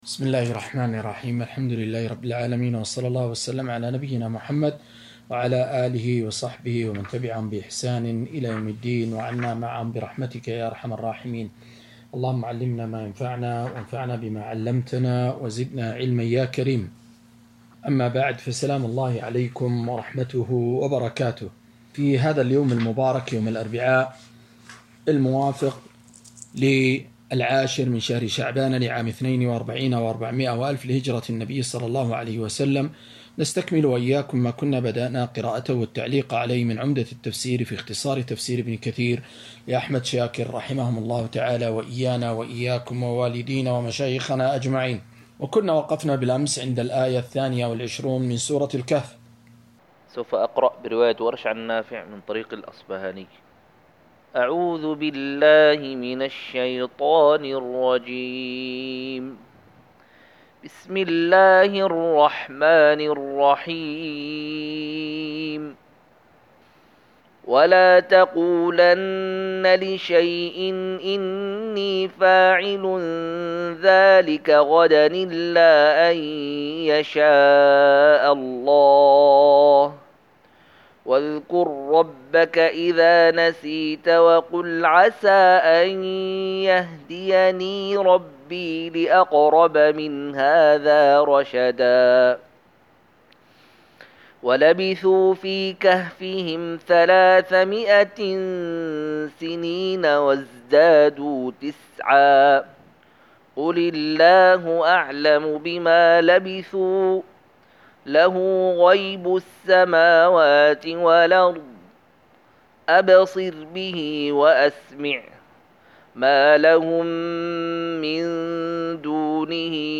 271- عمدة التفسير عن الحافظ ابن كثير رحمه الله للعلامة أحمد شاكر رحمه الله – قراءة وتعليق –